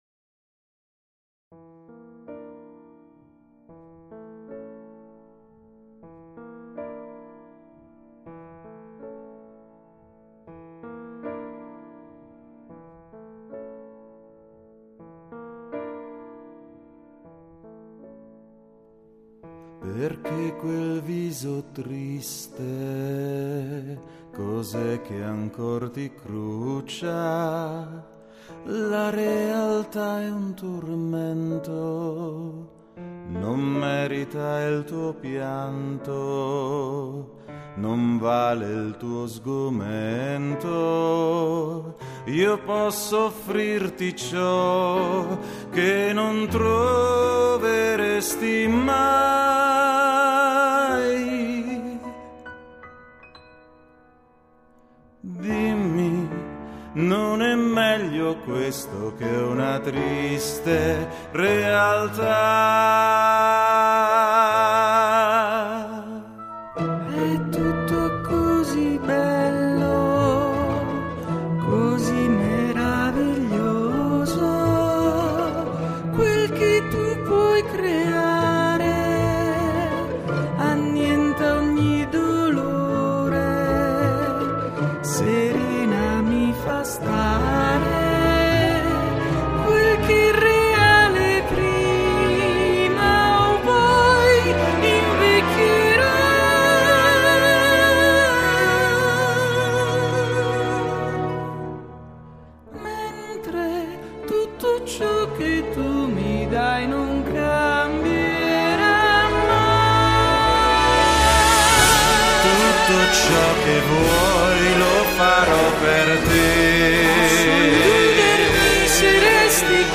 REGISTRO VOCALE: TENORE;